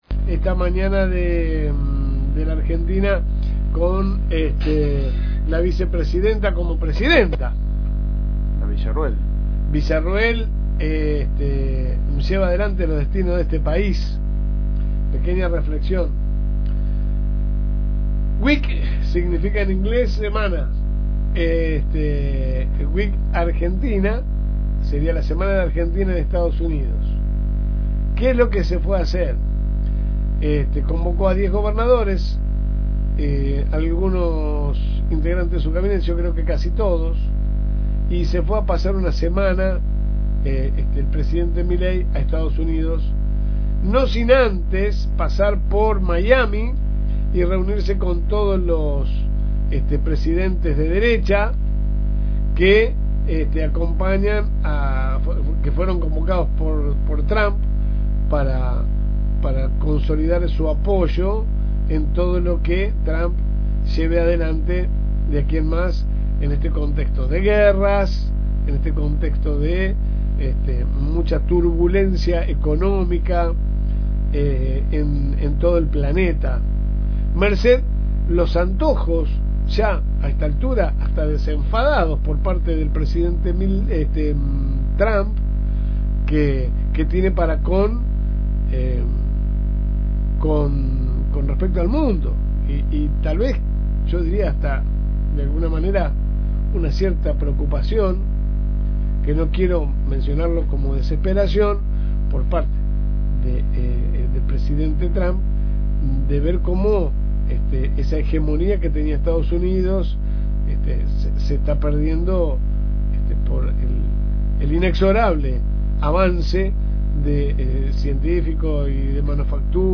La editorial a continuación: